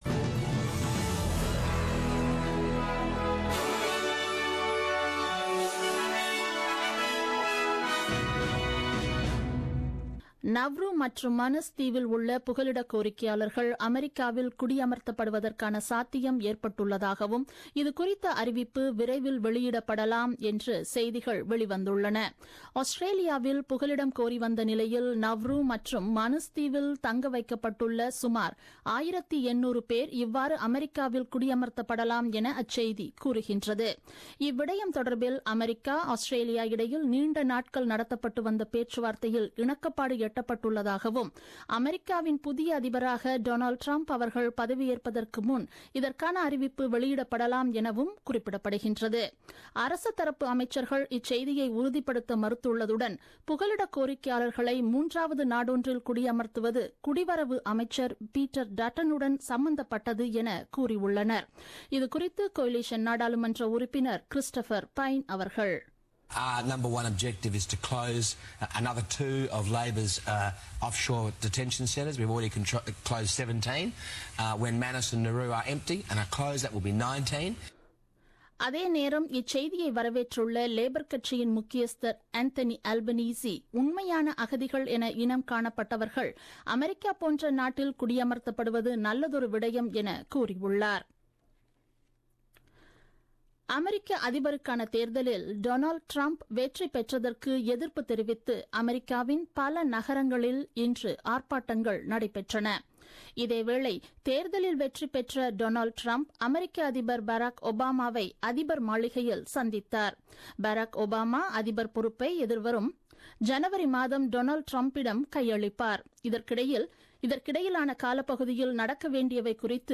The news bulletin broadcasted on 11 Nov 2016 at 8pm.